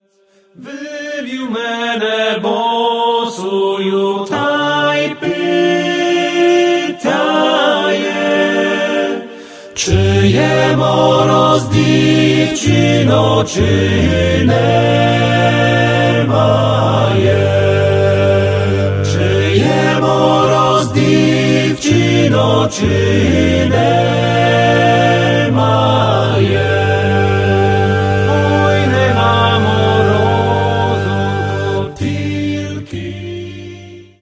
(sł. i mel. trad. )